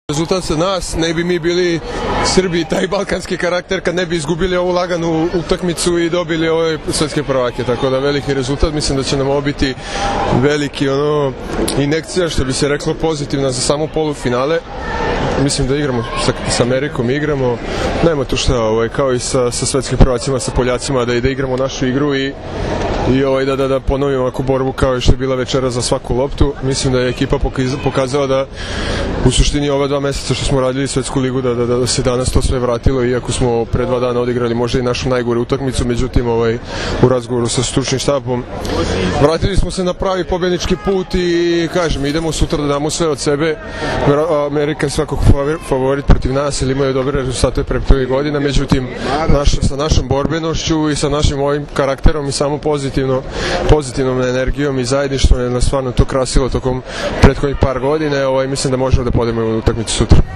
IZJAVA MARKA PODRAŠČANINA